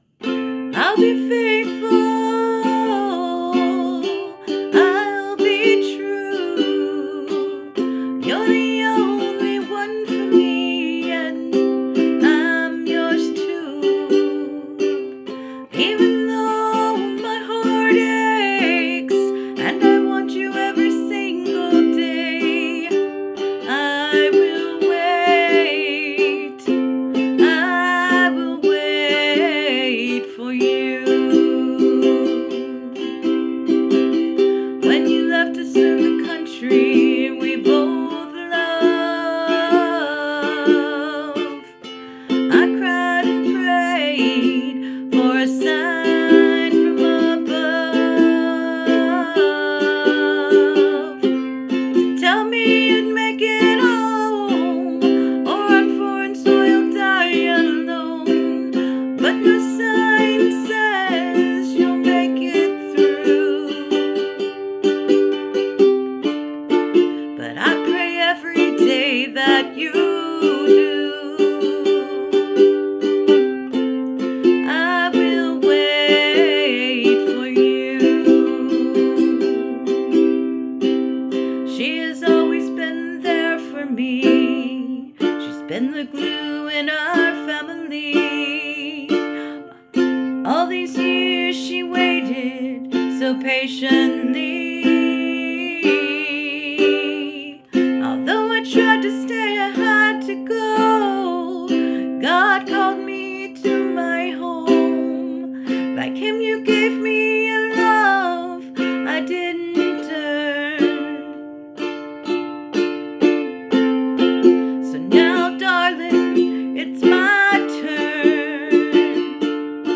I am reposting this song since I was able to add audio...I could only include a clip of what the tune/melody is supposed to sound like since it's a rather long song. I play the ukulele and sing but not very well lol... forgive any mistakes please.;) <3 This was written in honor of my Maternal grandparents.
Really like the addition of the vocals and your piece set to music.
The melody is good, though it can always be fine-tuned to smooth any rough edges.